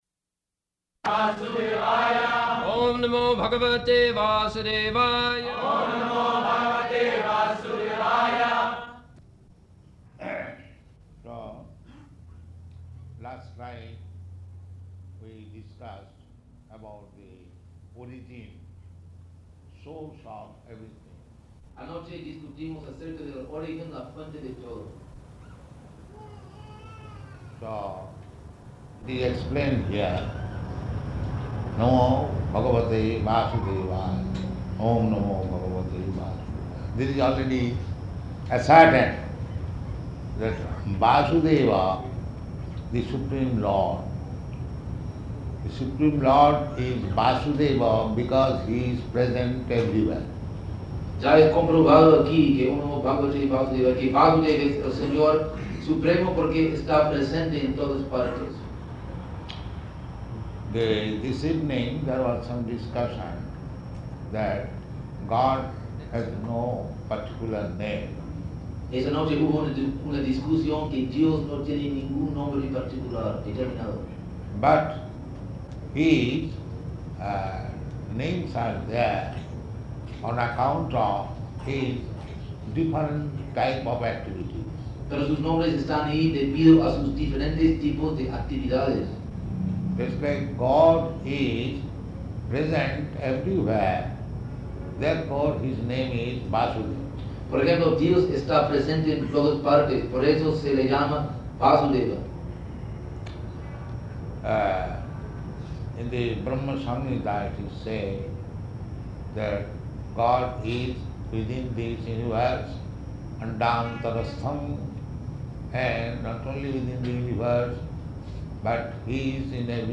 Location: Caracas
[devotees repeat] Prabhupāda: So last night we discussed about the origin, source of everything.